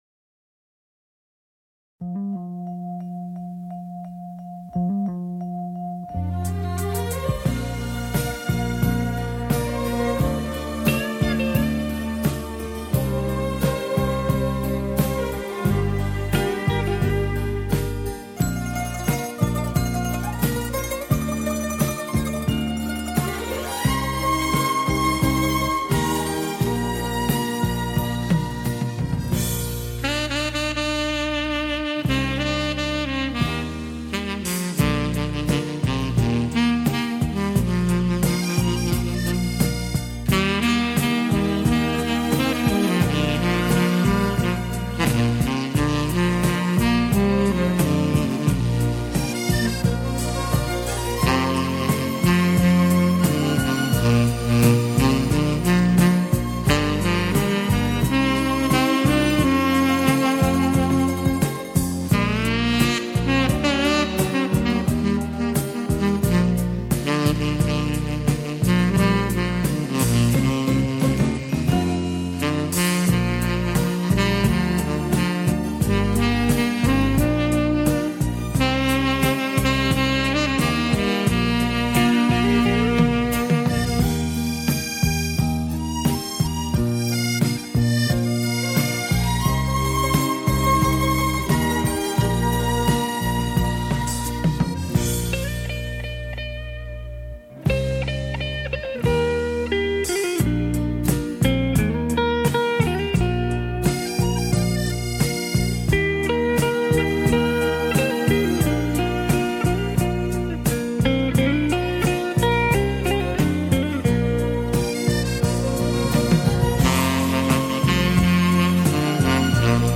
随着萨克斯风乐音的吹奏，
可让聆听的人能很自然的以轻松无压的情绪进入到音乐之中。
这轻柔音乐如水般拂过你的全身，